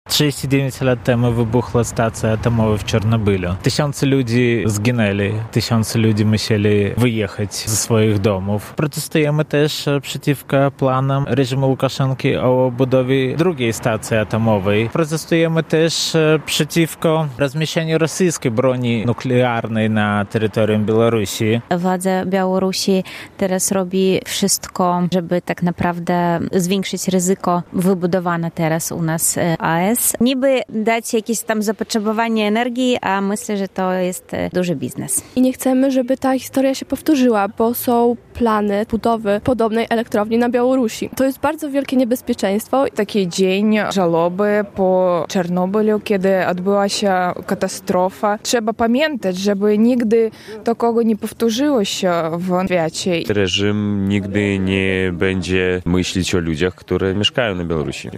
Spotkali się by upamiętnić ofiary Czarnobylskiej Elektrowni Atomowej, ale też zwrócić uwagę na zagrożenie ze strony reżimów Łukaszenki i Putina. W sobotę (26.04) w 39. rocznicę katastrofy w Czarnobylu kilkudziesięciu Białorusinów protestowało pod konsulatem Republiki Białoruś w Białymstoku.